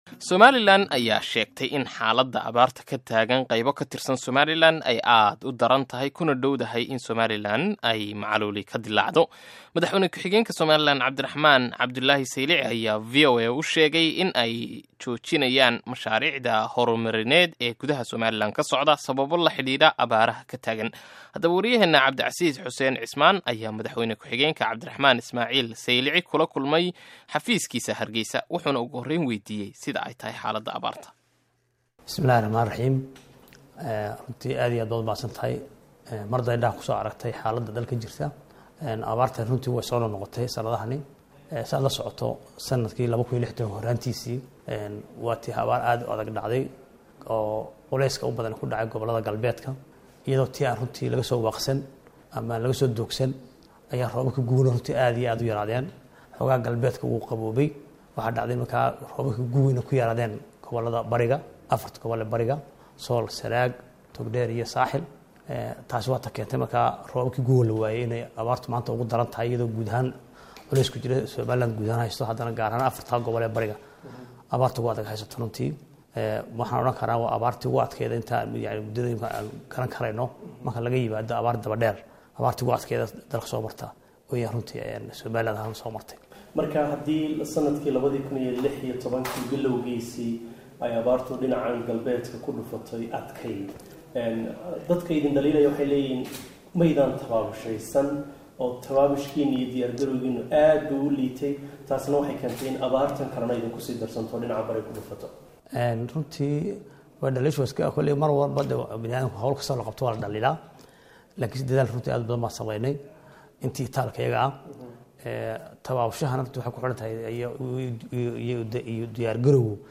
Wareysi: Madaxweyne Ku-xigeenka Somaliland
Dhageyso Wareysiga Seylici oo dhan